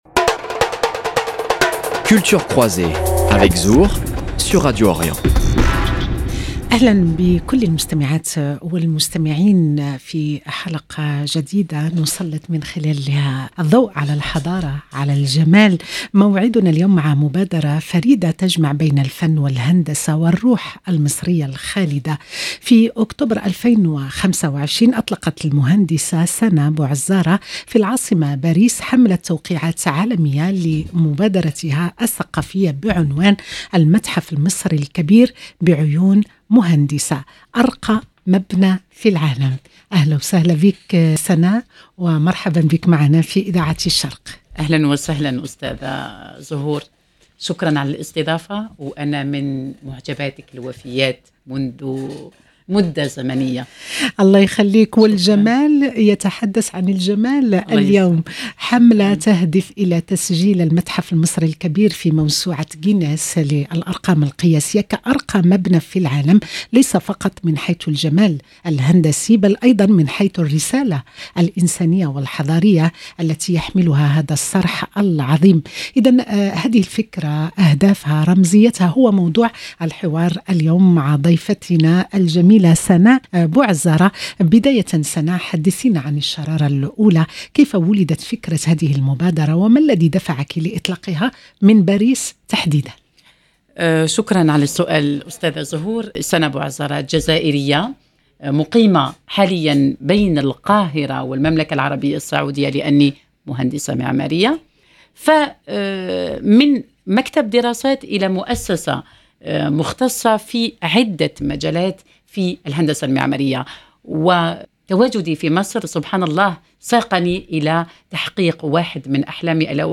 في هذا الحوار ضمن برنامج ثقافات متقاطعة